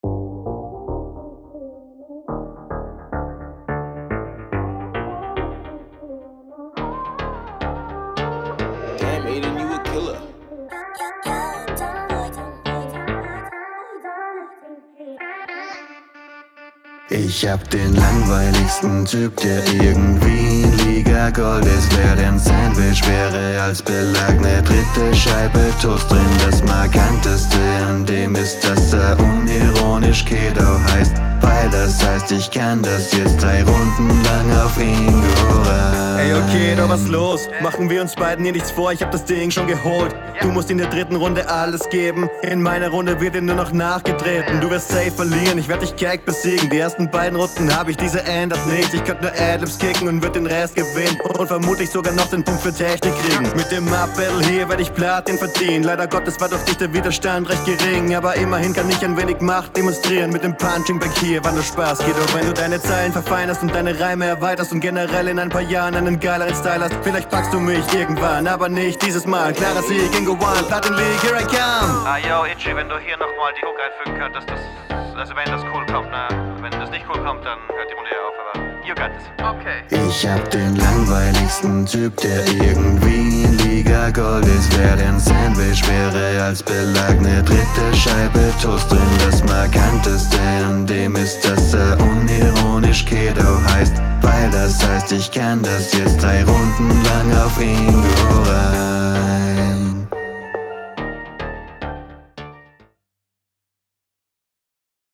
Hook etwas unverständlich, könnte aber an meiner Abhöre liegen.
Hier gefällt mir der Gesang nicht so ansonsten ist es wie du in der Runde …